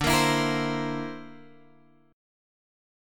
D 7th Suspended 2nd Sharp 5th